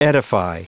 Prononciation du mot edify en anglais (fichier audio)
Prononciation du mot : edify